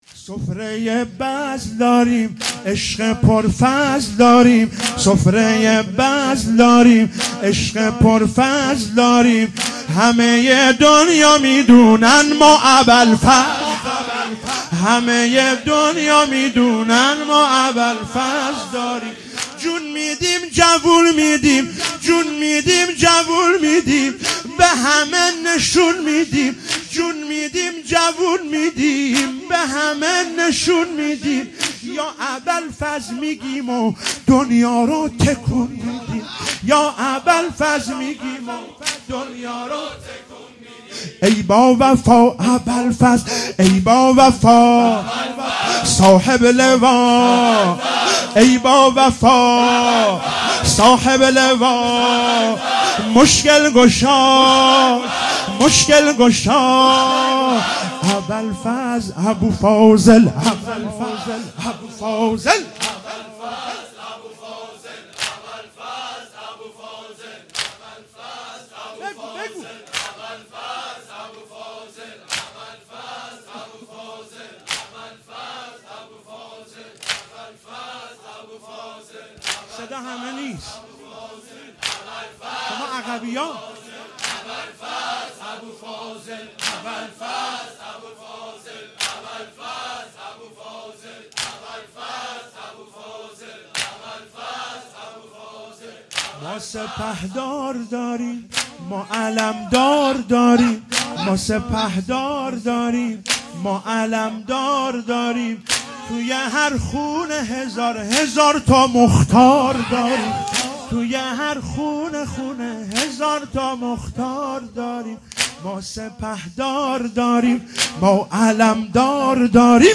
شب تاسوعا محرم 97 - واحد - سفره ی بزل داریم